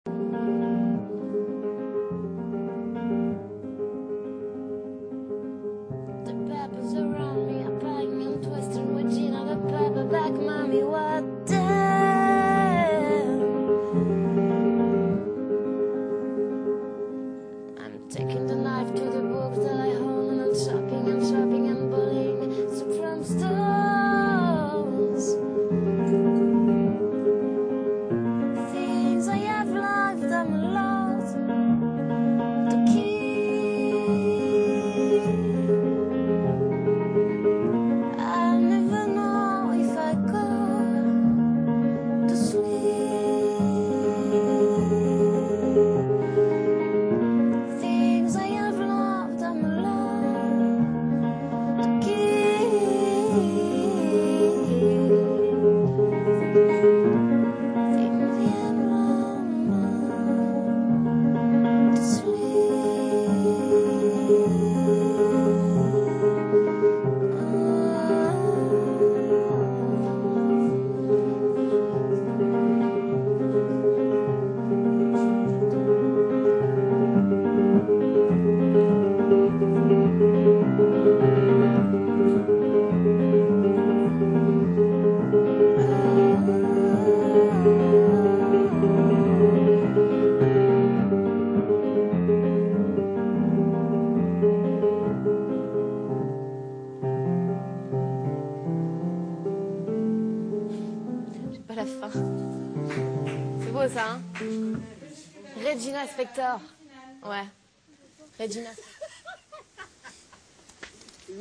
Piano/voix